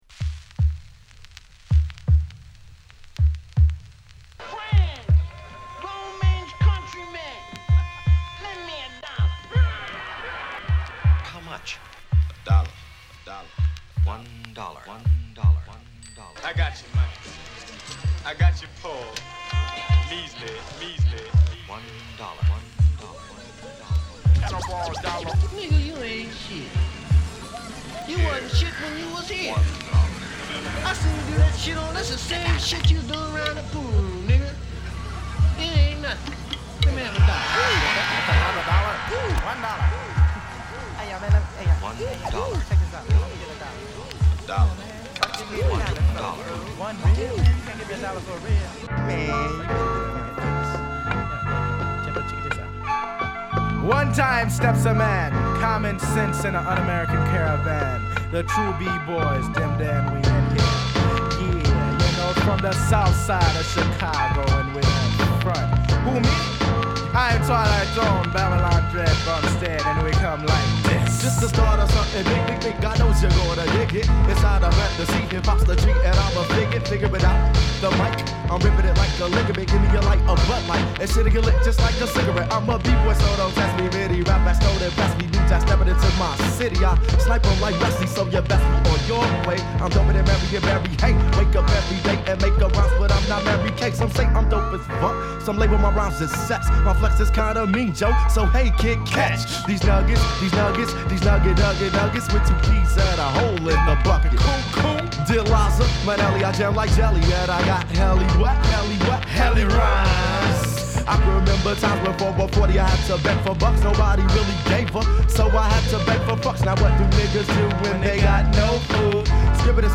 ＊試聴はA1→A3→E→Fです。